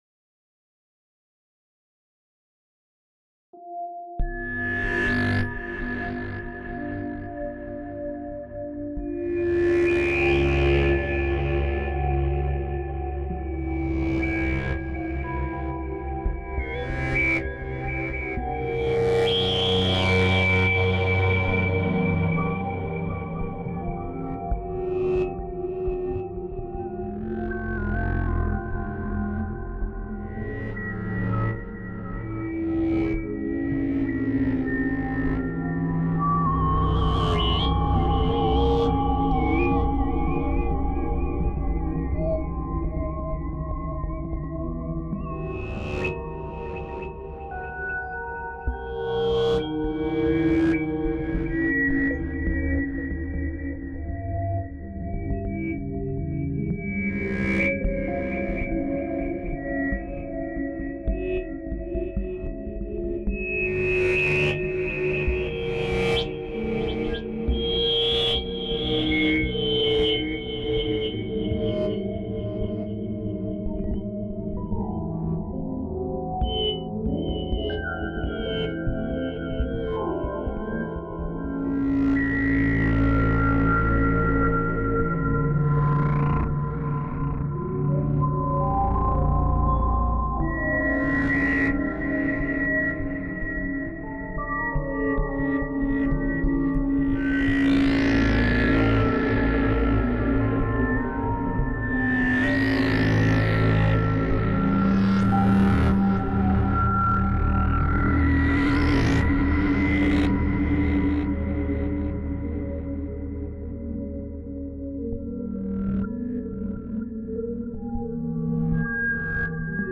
L’esempio allegato è stato realizzato con l’FM8, l’evoluzione del FM7, che amplia ulteriormente il potenziale creativo. Tra i suoni che ho apprezzato particolarmente, posso citare preset come Abandoned Toyfactory, con i suoi toni metallici e accattivanti, e Nebula, ideale per pad eterei e stratificati. Utilizzando questi timbri con la chitarra MIDI, ho potuto creare sonorità che fondono l’organico e il digitale, spingendo i confini timbrici dello strumento verso nuove direzioni.
FM8_abondaned_The_factory.wav